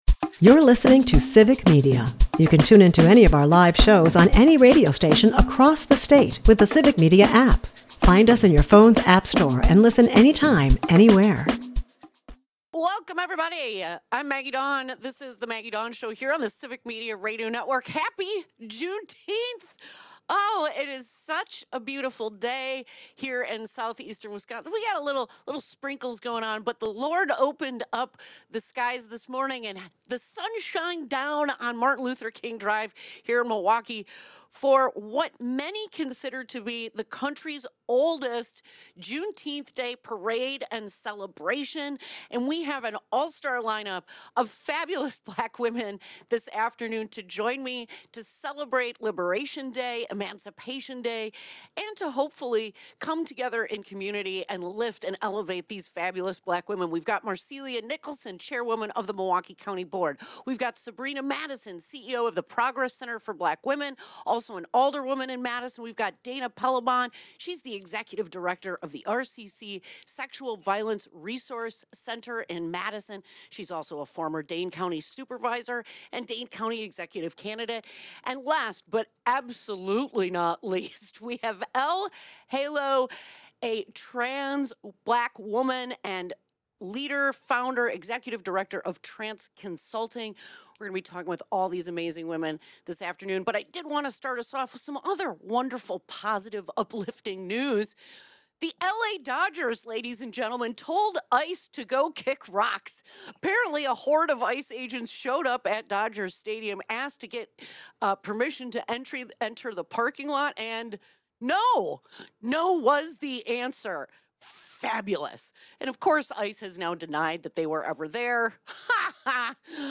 Milwaukee County Chairwoman Marcelia Nicholson joins a panel of dynamic voices to discuss the systemic barriers Black women face—from workplace inequity to political marginalization—and the strength it takes to lead despite them.